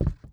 Footstep_Wood 02.wav